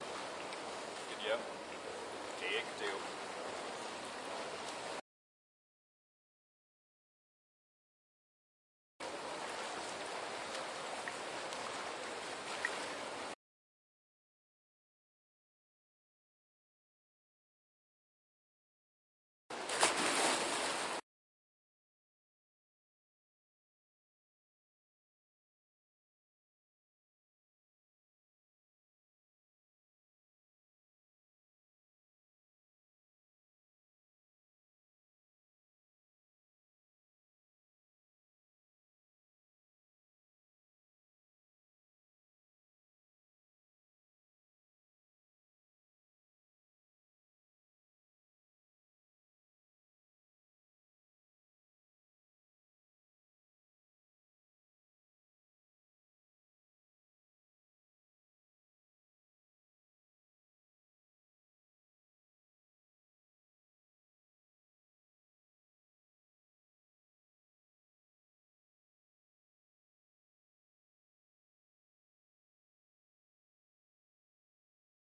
这正是你所期望的一个人跳入游泳池的声音。有一点背景噪音，可以很容易地编辑出来。
Tag: 泳池 飞溅 潜水 跳水